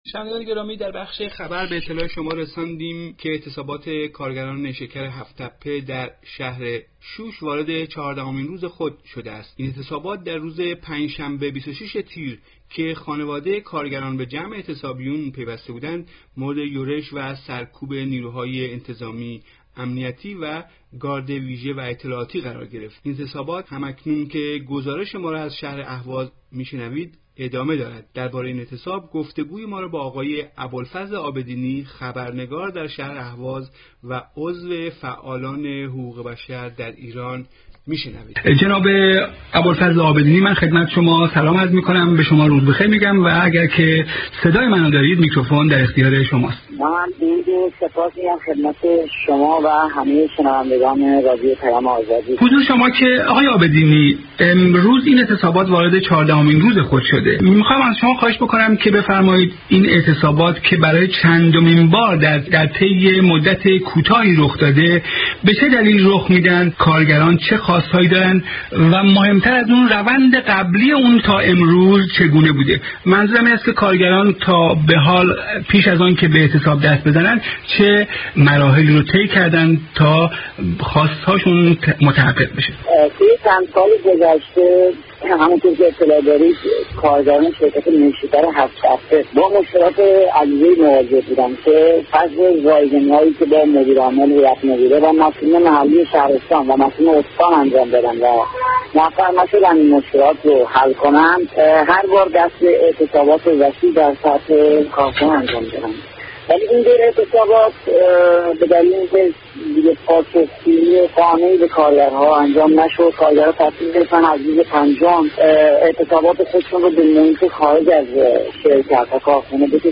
گفتگوی رادیو پیام آزادی